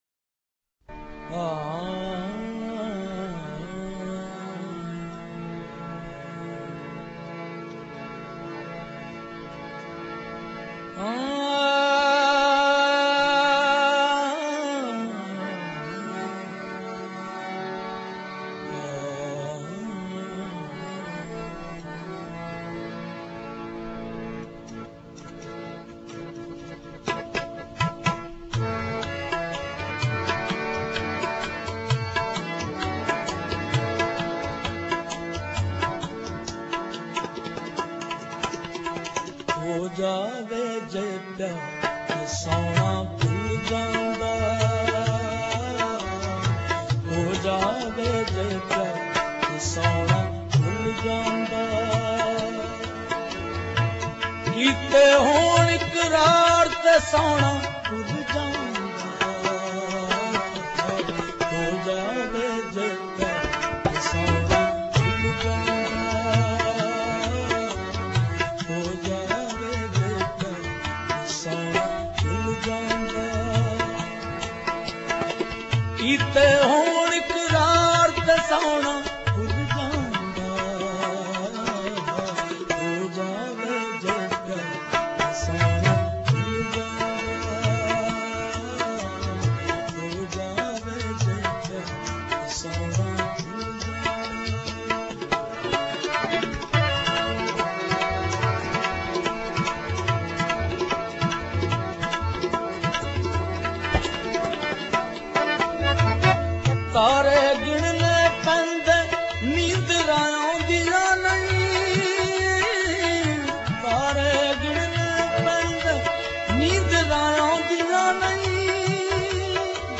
Download MP3 Worlds Largest Collection of Qawwali
Kalaam/Poetry , Punjabi
Punjabi folk track